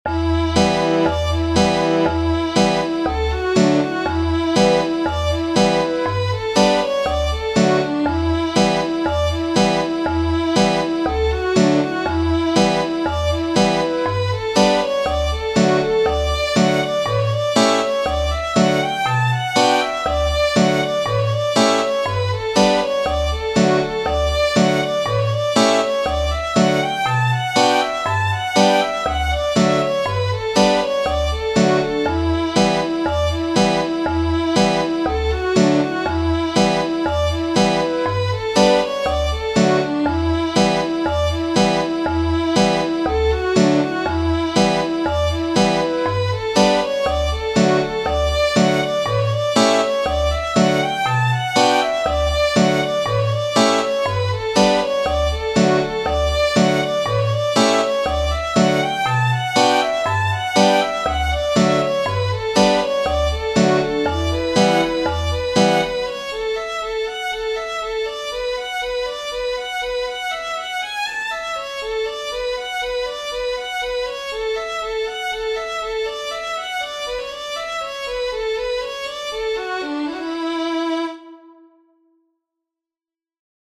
It is a tune I added to my repertoire as I promised, but I've recently been re-visiting some of my favourite fiddle tunes and creating (very simple) backing tracks with MuseScore.
So - here's a couple of play-along audio tracks for Drowsy Maggie - the first has four instruments (all synth of course) - flute/vibraphone/guitar/fiddle.
drowsy_maggie-ensemble.mp3